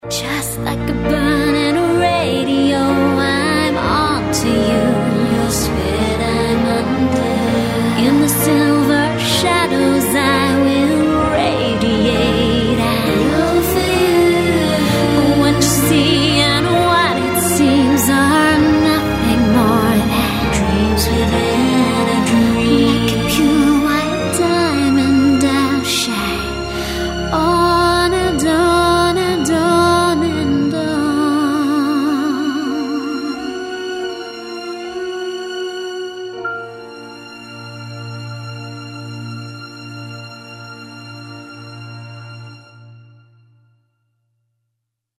• Качество: 128, Stereo
мелодичные
спокойные